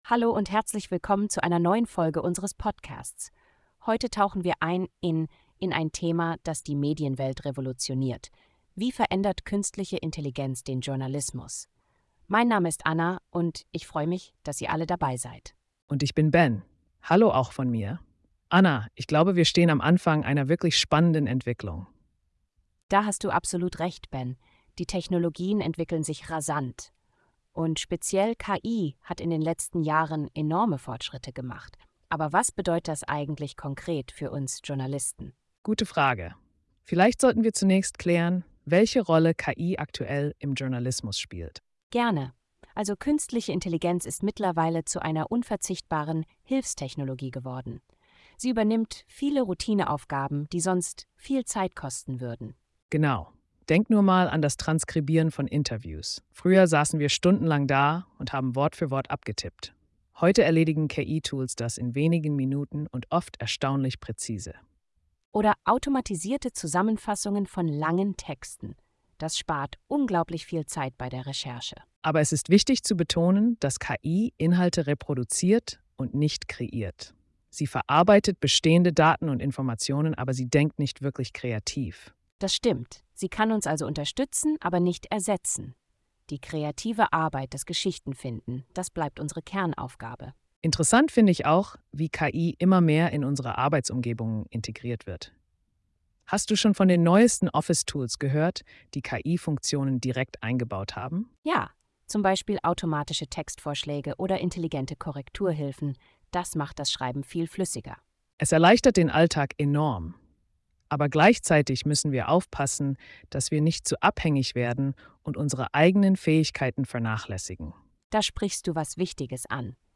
Unsere KI-Hosts diskutieren, wie KI Berufsbilder transformiert und neue Chancen für Journalisten eröffnet.